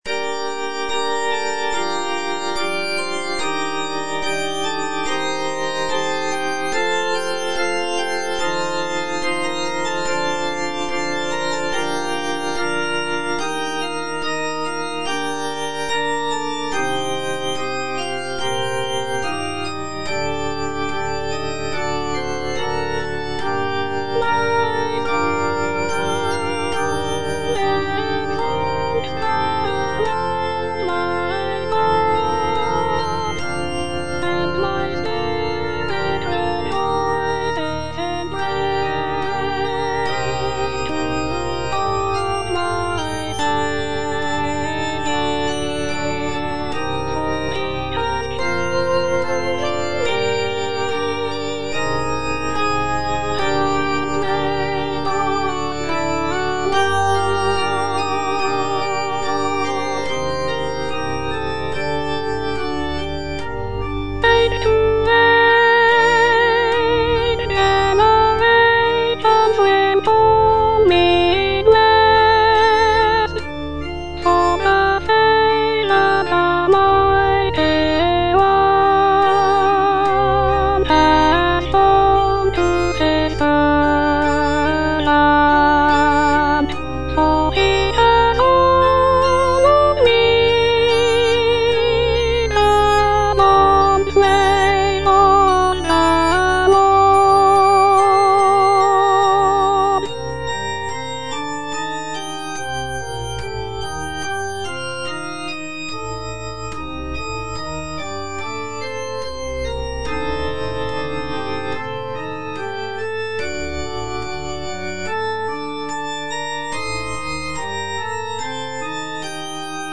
Soprano (Voice with metronome)
choral piece